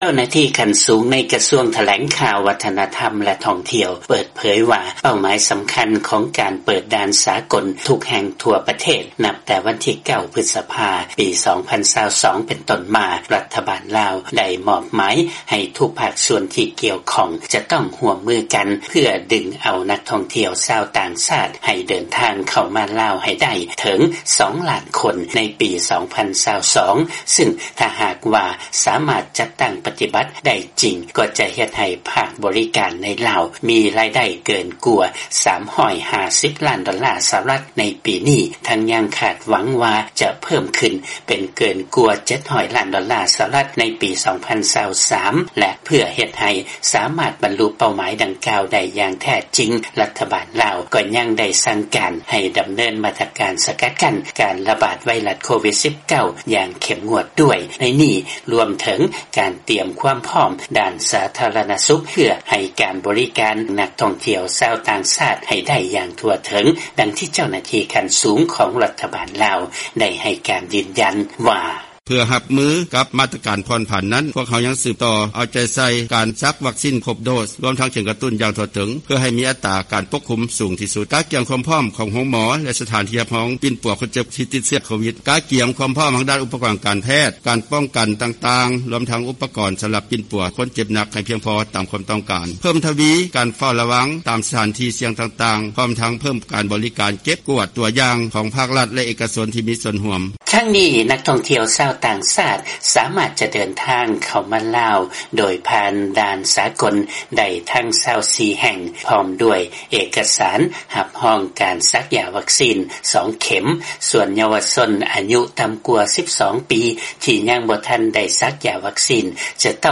ເຊີນຟັງລາຍງານກ່ຽວກັບການກະກຽມດ້ານສາທາລະນະສຸກເພື່ອຕ້ອນຮັບນັກທ່ອງທ່ຽວເຂົ້າໄປລາວ